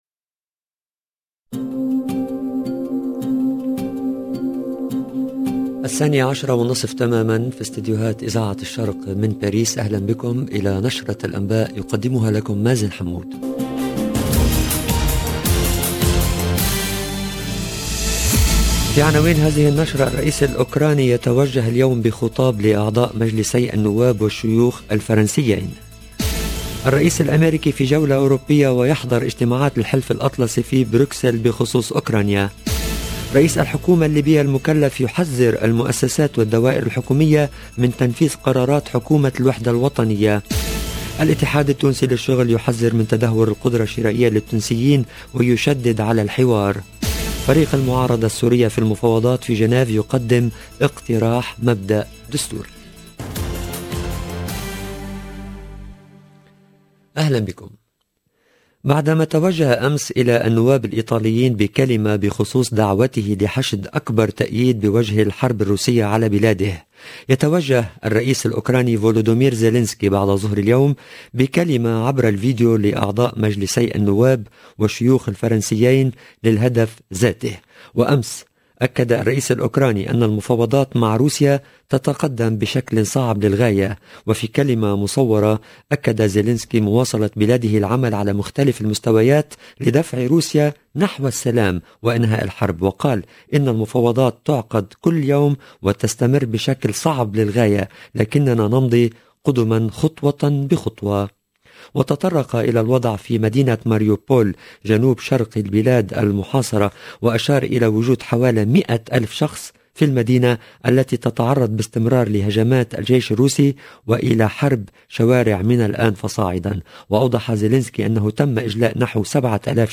LE JOURNAL DE MIDI 30 EN LANGUE ARABE DU 23/03/22